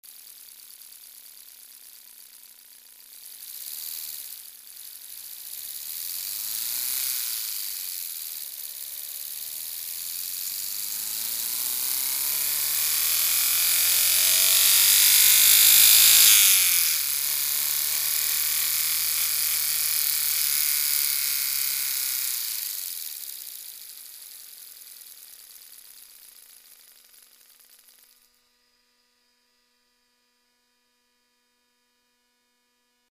pre zaujimavost nahral som zvuk motora zo snimaca klepania, motor favo 1.3
motor_01.mp3